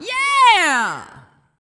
47 RSS-VOX.wav